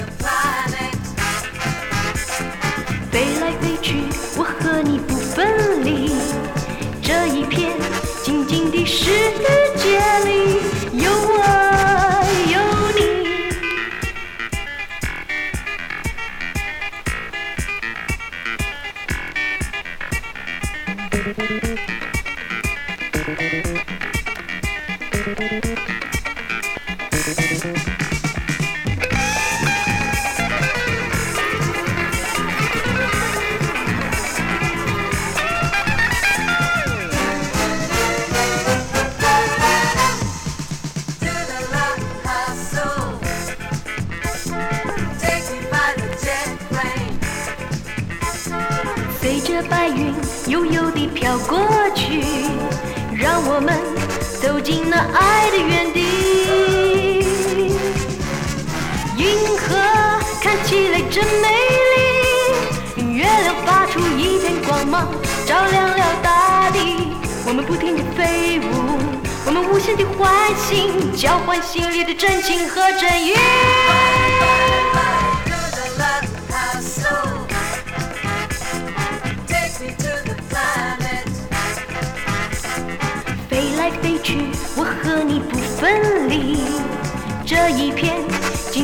※チリノイズ出る箇所有。